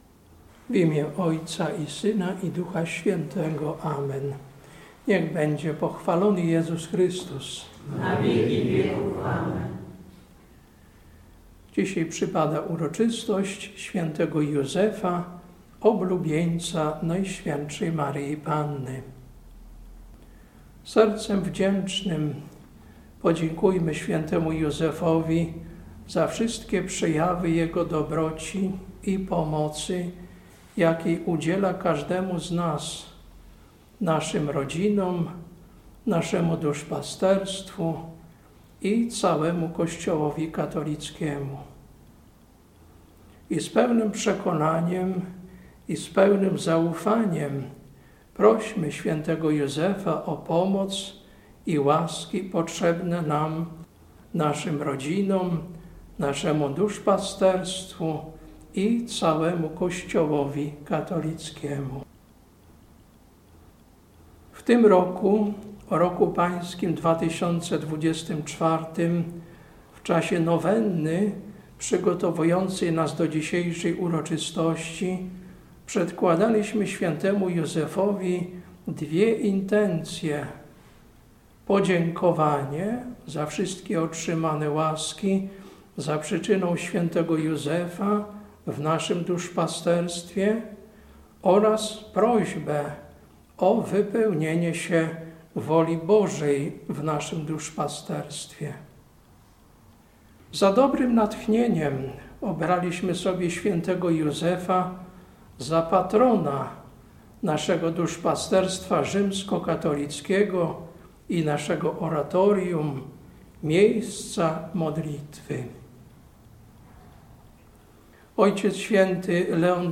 Kazanie na uroczystość Św. Józefa, Oblubieńca, NMP, Wyznawcy, 19.03.2024 Lekcja: Mdr 45, 1-6 Ewangelia: Mt 1, 18-21